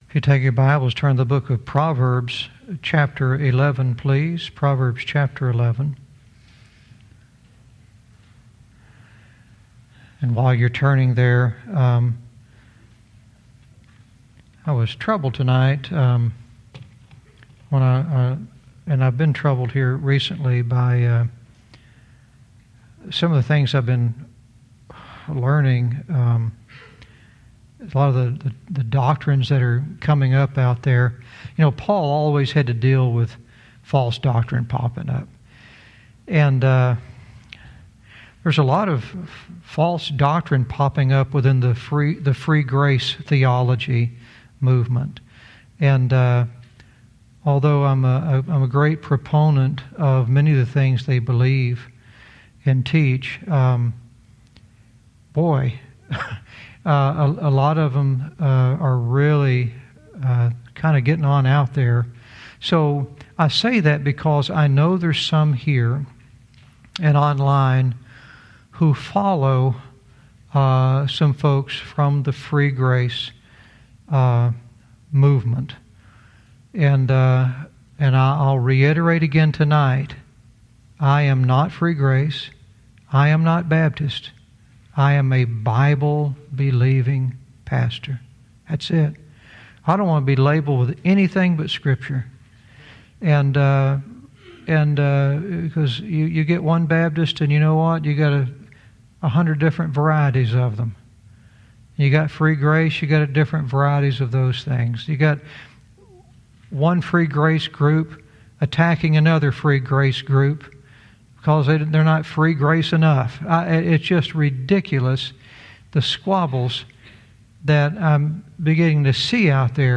Verse by verse teaching - Proverbs 11:17 "It's Good for You"